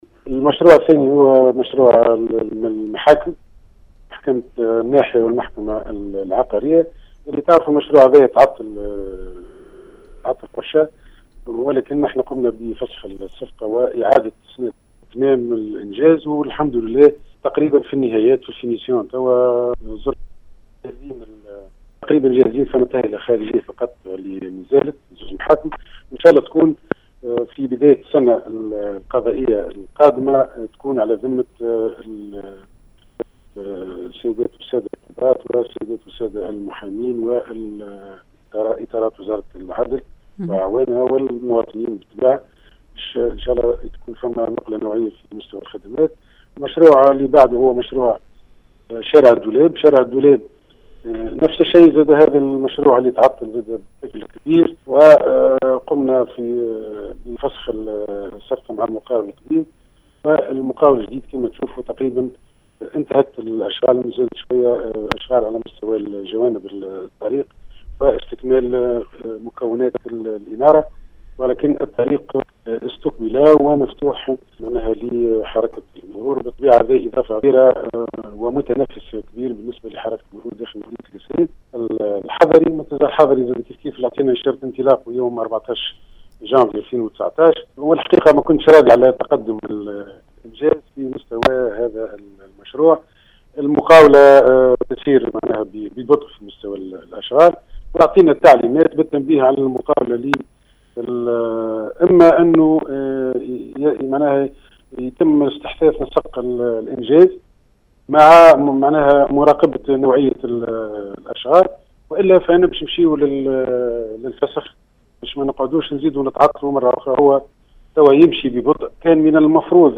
كما تفقد بوقديدة أشغال تهيئة منتزه وادي الدرب بالجهة ومحكمة الناحية والمحكمة العقارية وشارع الدولاب للوقوف على مدى تقدم هذه المشاريع التي استانفت بعد طول تعطلها ، وحسب ما افادنا به والي القصرين في تصريح هاتفي فان الاشغال تشهد تقدما ملحوظا ، مشيرا ان اغلبها تم فسخ العقود مع المقاولين المشرفين وتغييرهم .